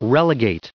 added pronounciation and merriam webster audio
1535_relegate.ogg